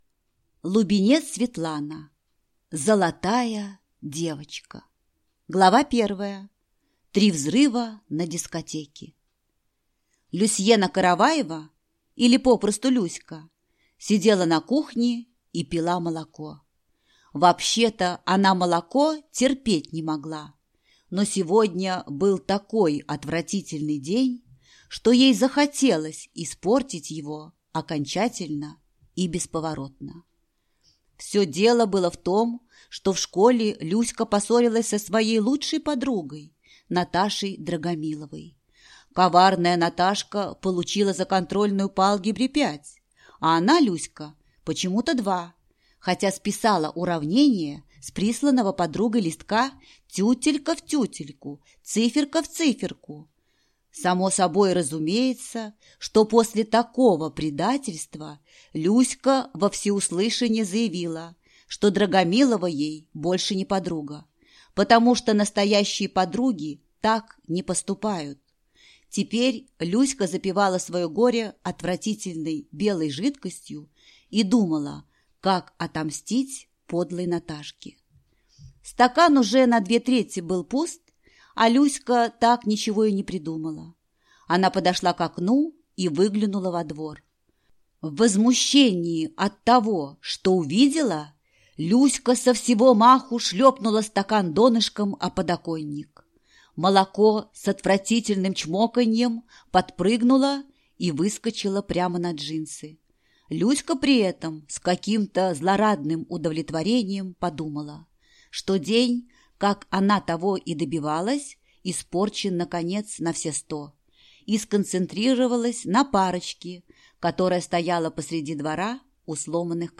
Аудиокнига Золотая девочка | Библиотека аудиокниг